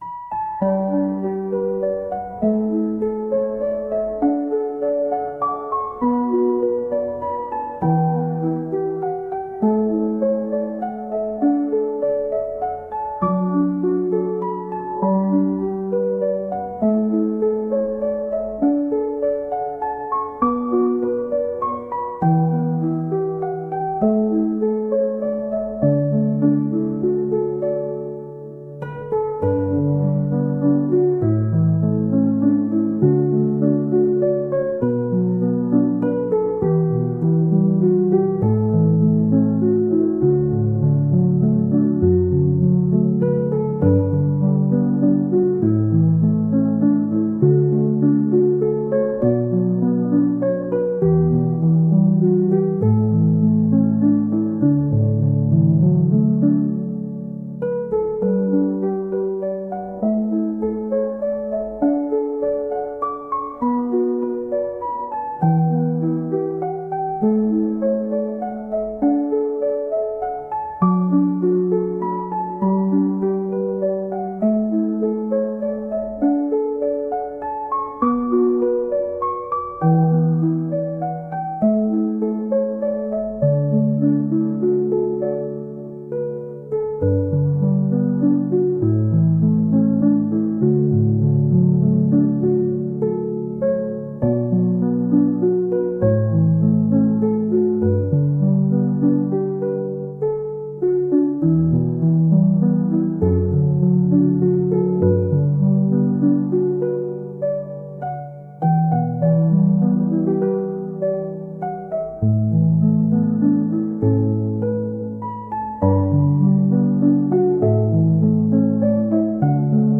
癒し、リラックス